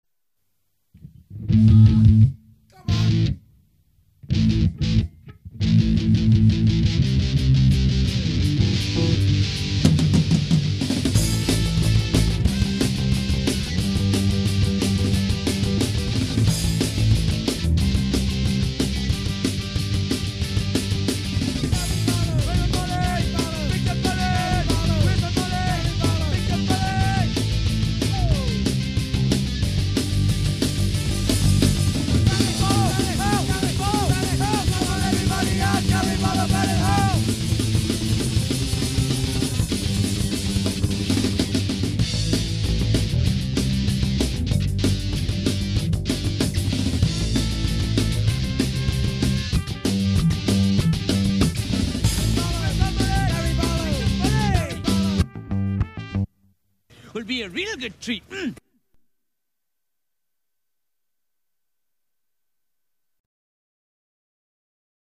----East coast/West coast Hardcore, Gregorian Chant----